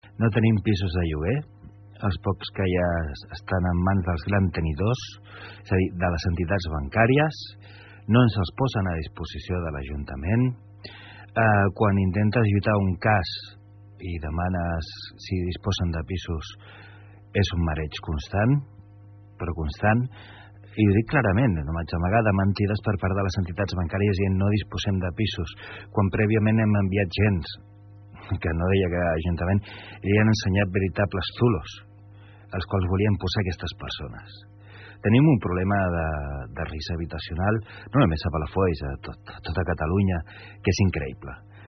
Així ho afirma el responsable de la regidoria d’Habitatge i Gestió de la Pobresa Juan Andrés Osorio, que ahir va passar pel programa Assumptes Interns.